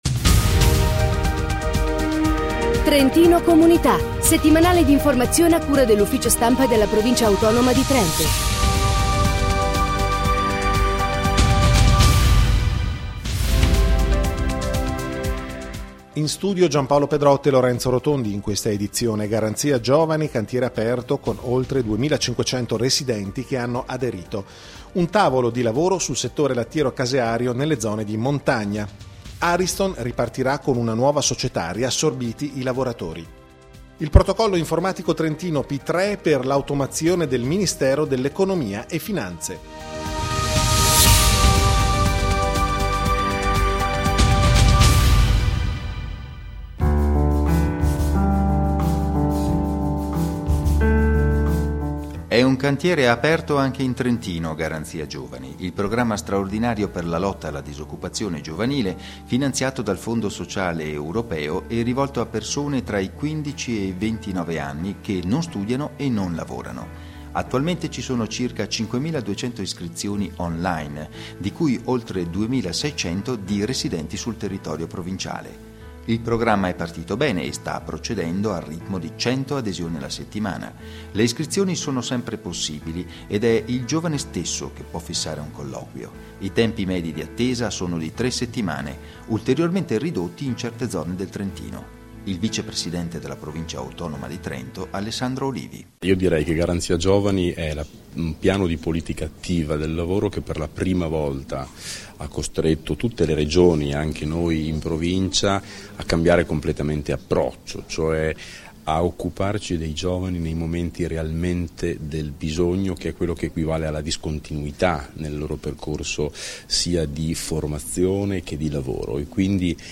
Notiziario Economia, imprese e attività produttive Famiglia, sociale e comunità Lavoro e occupazione